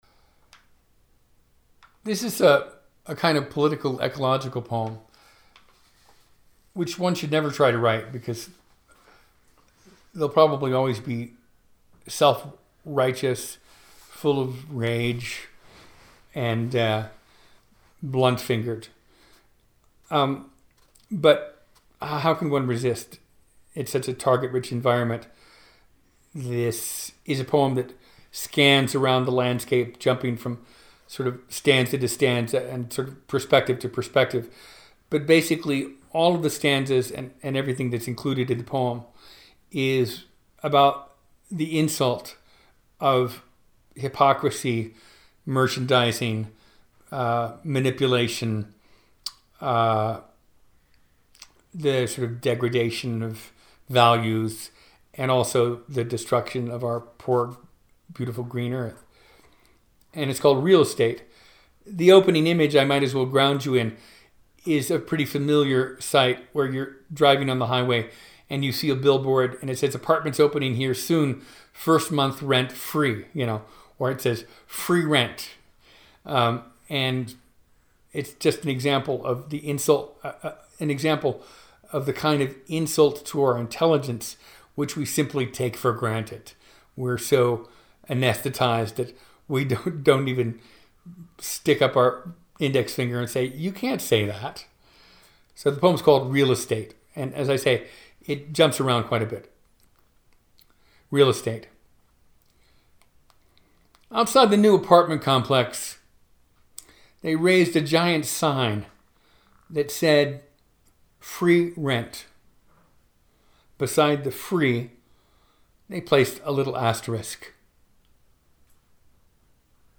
In the summer of 2018 Tony recorded himself reading twenty of those poems.